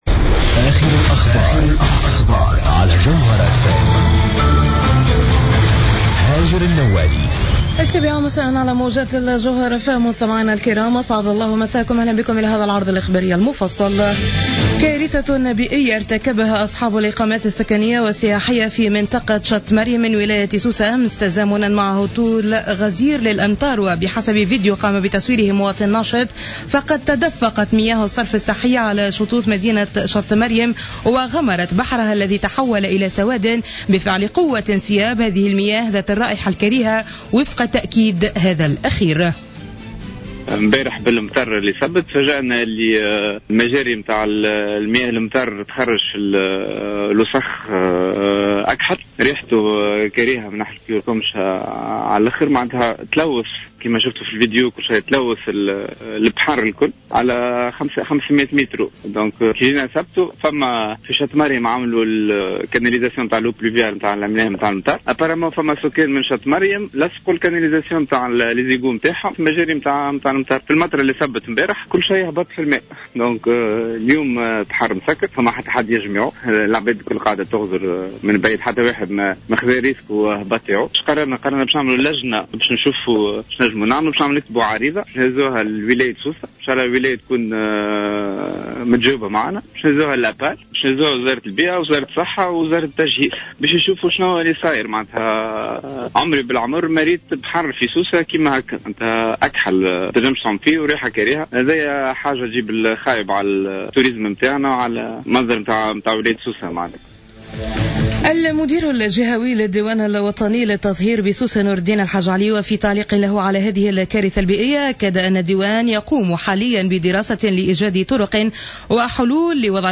نشرة أخبار السابعة مساء ليوم الأحد 16 أوت 2015